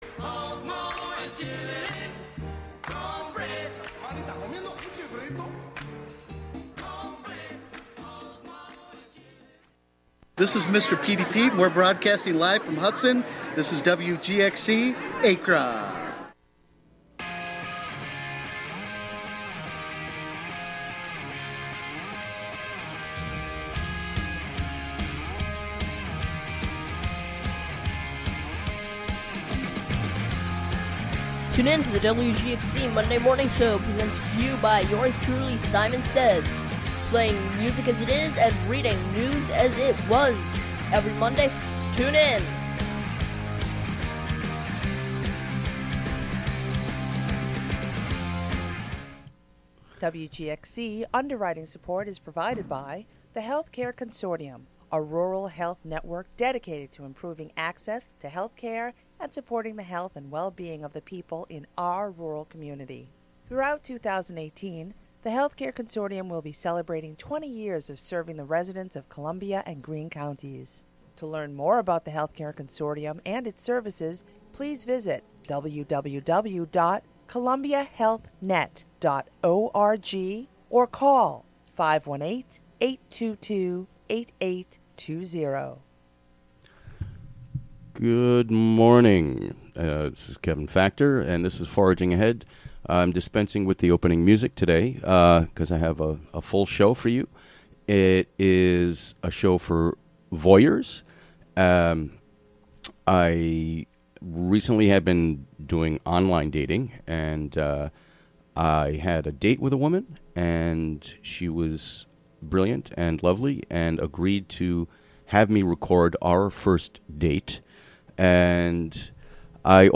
12pm Monthly program featuring music and interviews.